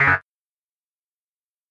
В коллекции представлены различные варианты предупреждающих сигналов, блокировок и системных оповещений.
Звук ошибки доступ запрещен